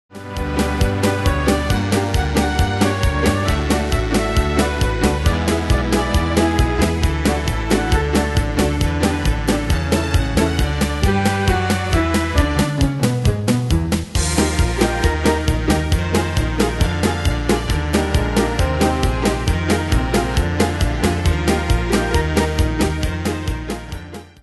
Style: Retro Ane/Year: 1932 Tempo: 135 Durée/Time: 2.30
Danse/Dance: Oldies Cat Id.
Pro Backing Tracks